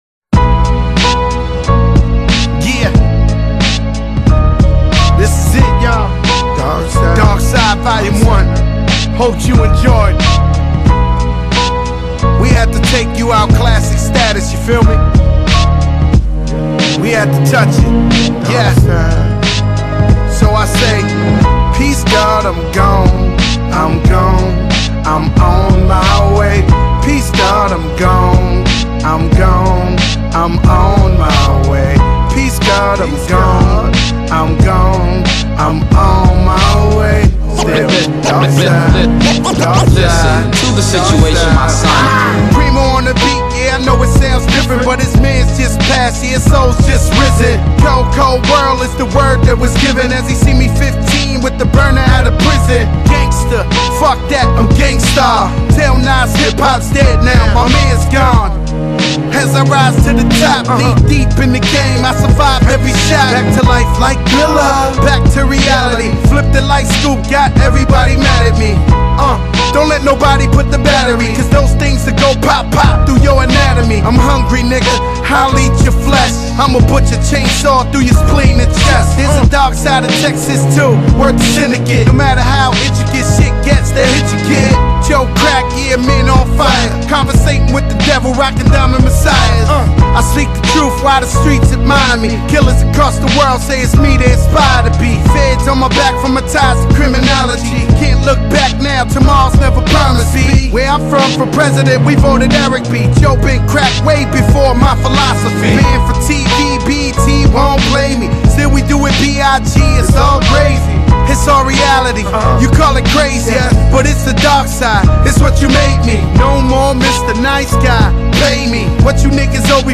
音樂風格︰Hip-Hop | 1CD |